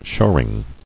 [shor·ing]